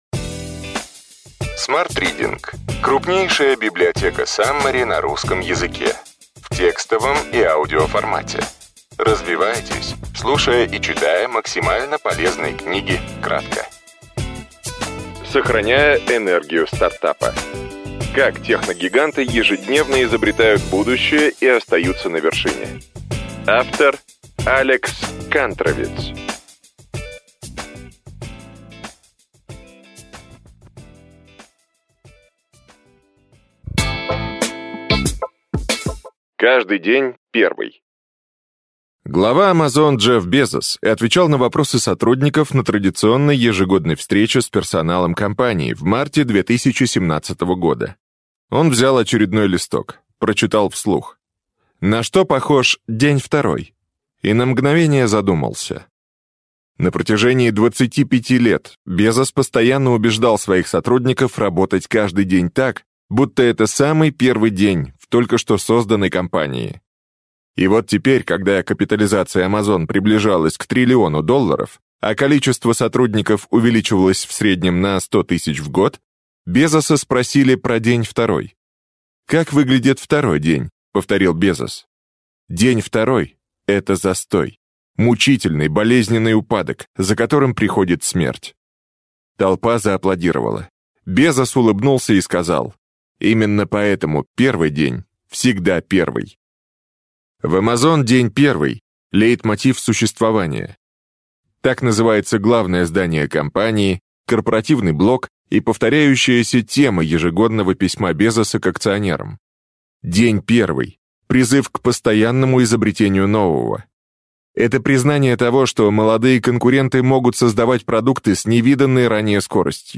Аудиокнига Сохраняя энергию стартапа.